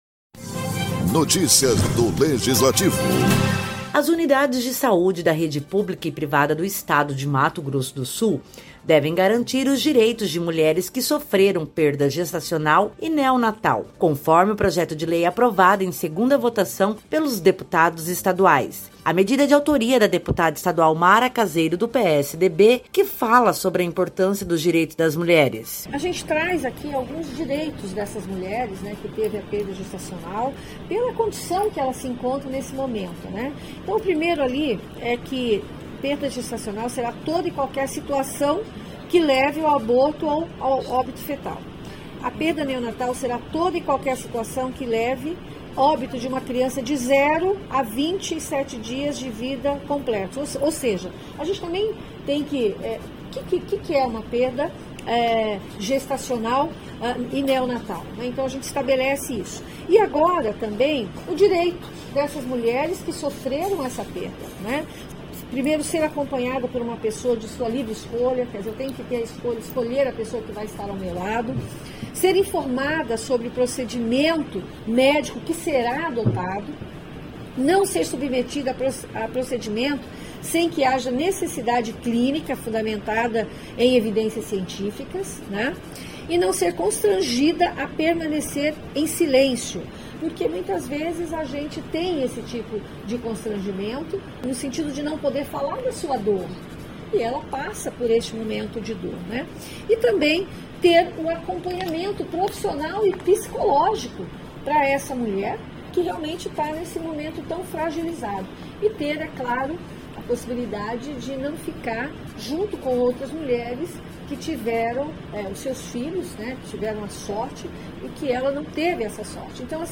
Produção e Locução: